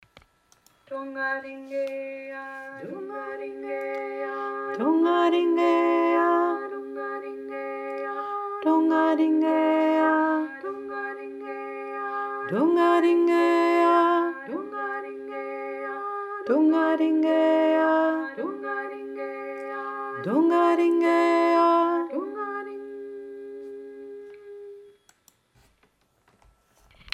probiert es einmal mit mir gemeinsam, ich singe vor ihr singt nach:
abwechselnd dur moll
dur-moll-abwechselnd.mp3